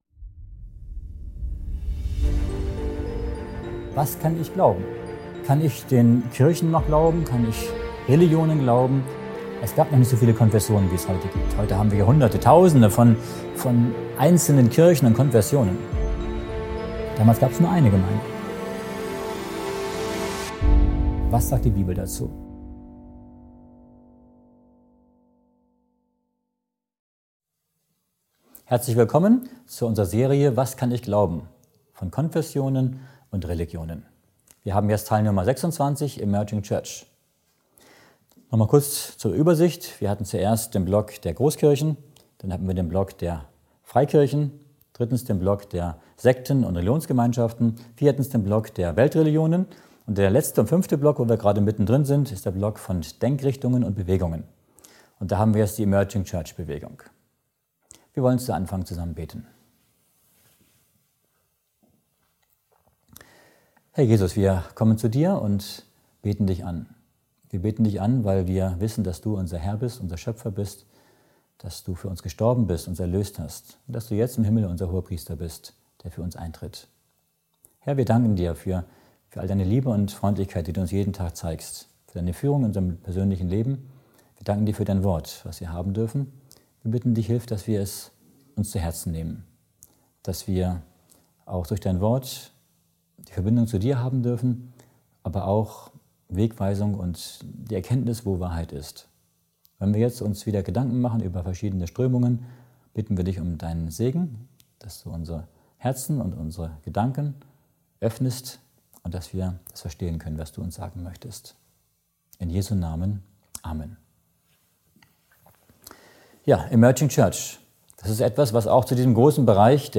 Ein anregender Vortrag für alle, die auf der Suche nach Orientierung sind.